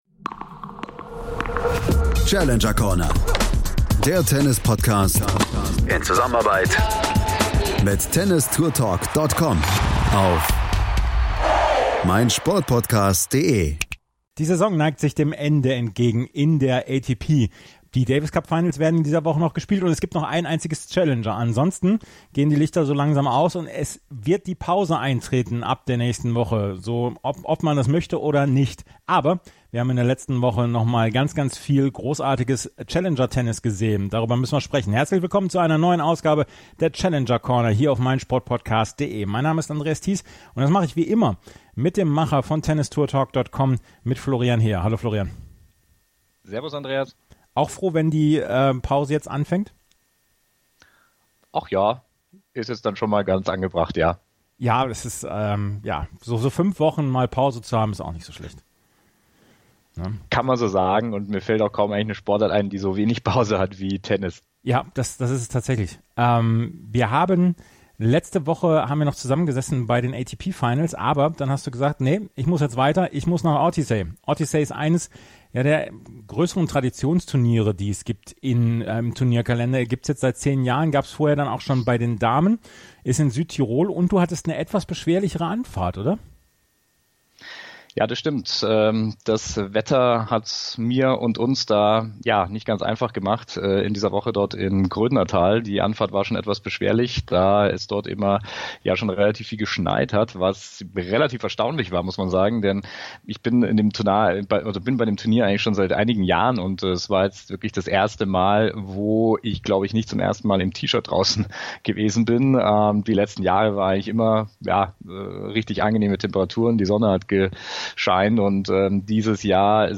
Er sprach unter anderem mit dem Finalgegner Sinners, mit Sebastian Ofner.